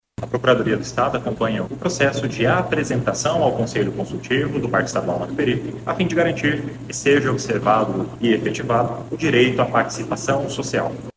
O procurador de Estado chefe da Procuradoria de Meio Ambiente, José Gebran destaca a importância da participação da Procuradoria Geral do Estado do Amazonas (PGE-AM) para a garantia dos direitos populares durante todo o processo.